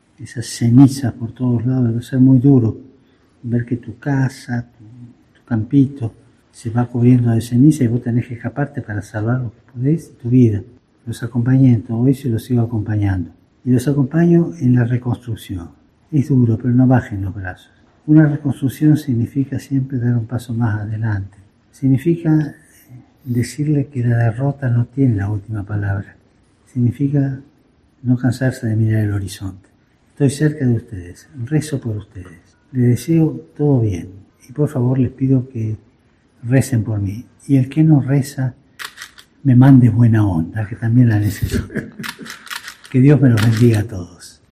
Mensaje del Papa Francisco a los afectados por la erupción